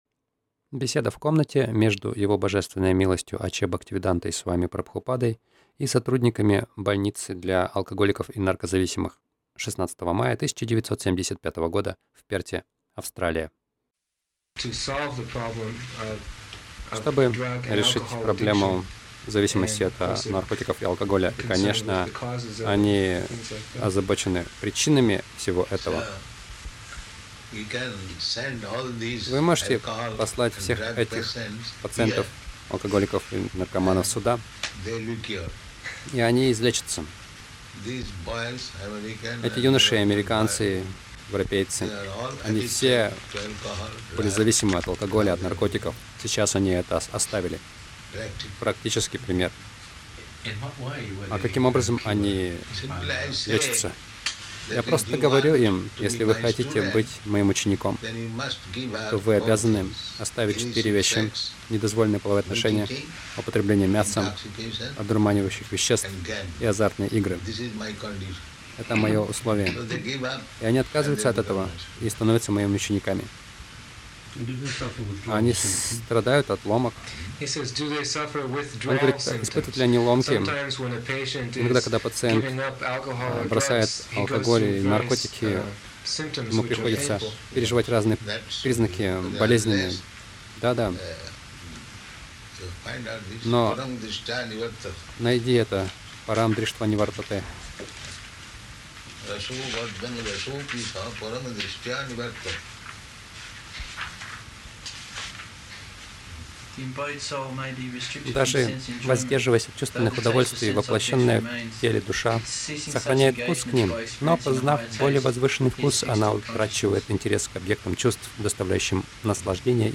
Беседа — Сонание Кришны лечит людей